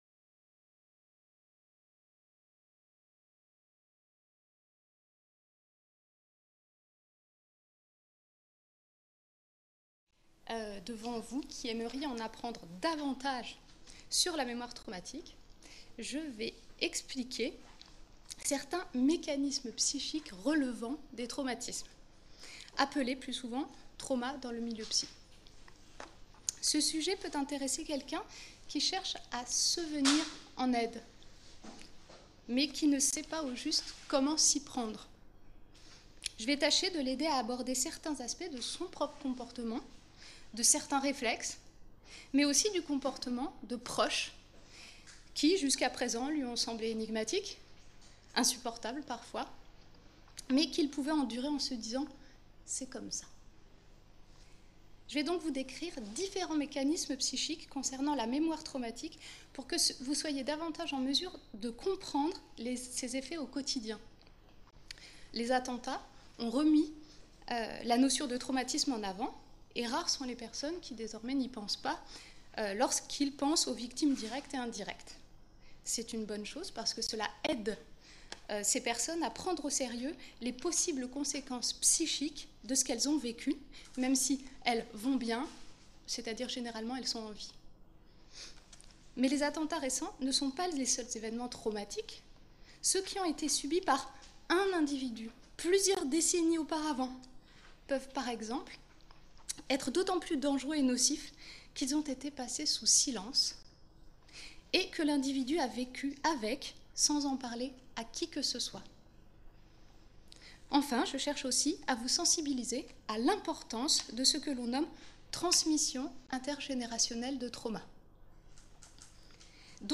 Cette conférence a été donnée dans le cadre de la deuxième édition de la Semaine de la Mémoire qui s'est déroulée à Caen du 19 au 23 septembre 2016.